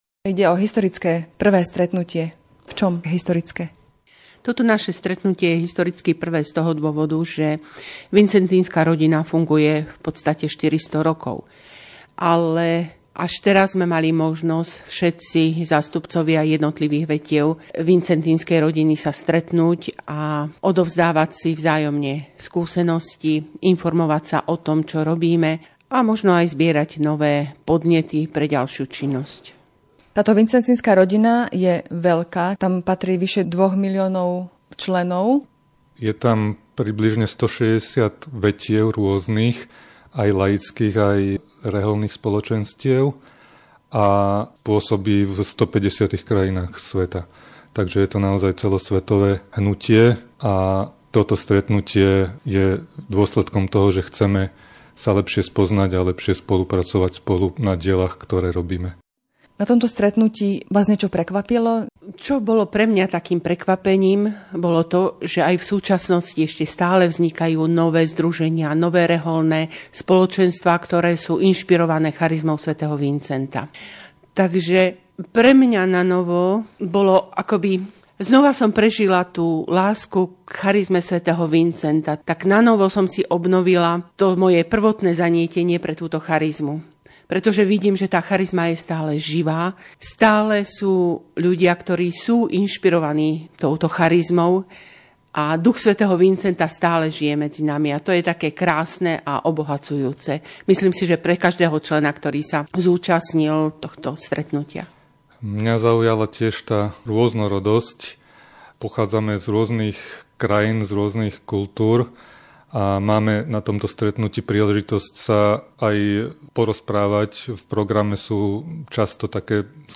v rozhovore pre Vatikánsky rozhlas.